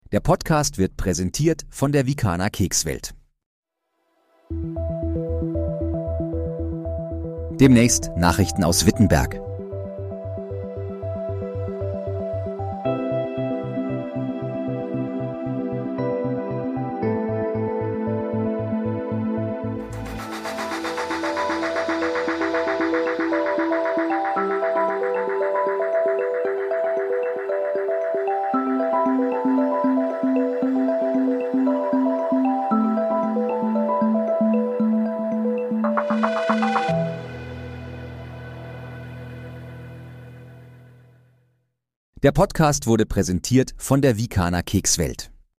Guten Abend, Wittenberg: Trailer, erstellt mit KI-Unterstützung